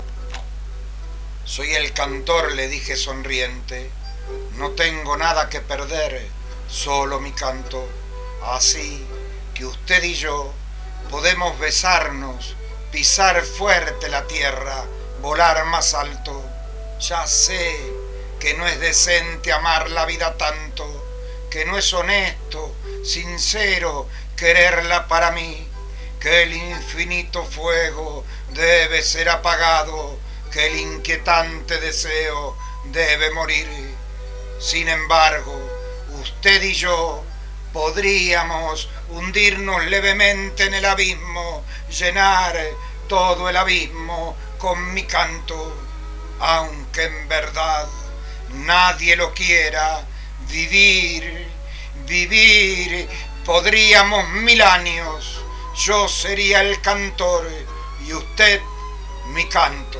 recites